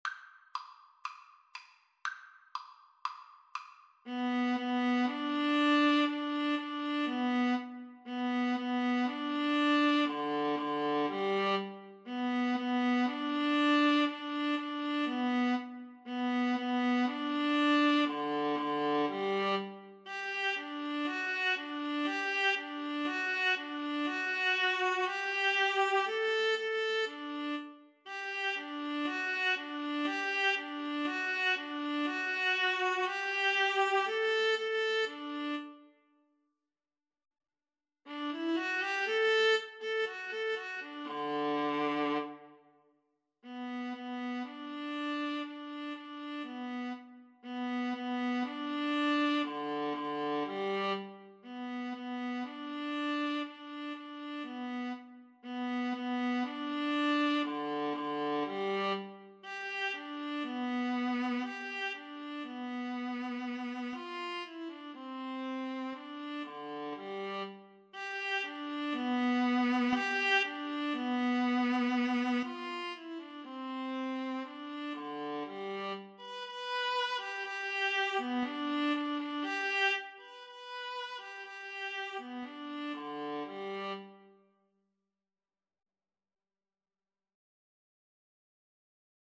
Free Sheet music for Viola Duet
Viola 1Viola 1Viola 2
G major (Sounding Pitch) (View more G major Music for Viola Duet )
2/4 (View more 2/4 Music)
No. 8. Allegro (View more music marked Allegro)
Viola Duet  (View more Easy Viola Duet Music)
Classical (View more Classical Viola Duet Music)